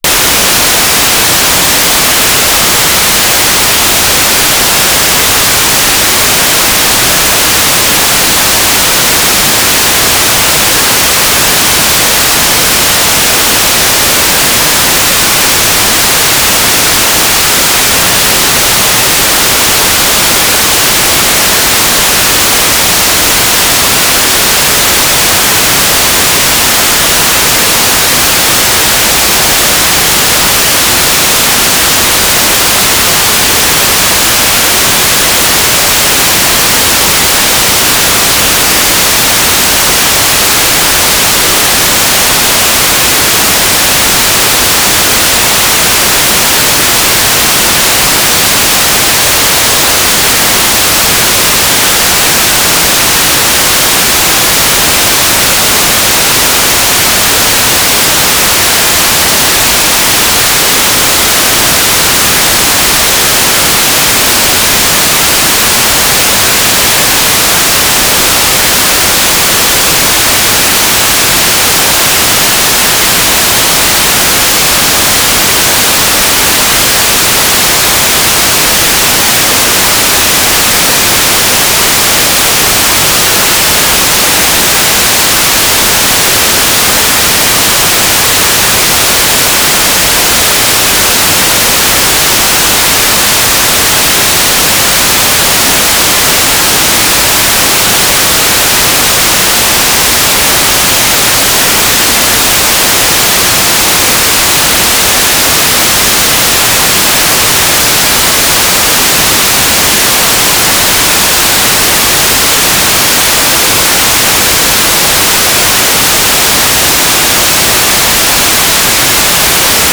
"transmitter_mode": "USB",